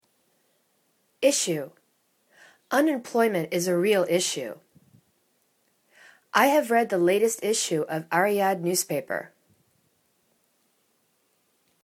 is.sue /'ishu:/ [C]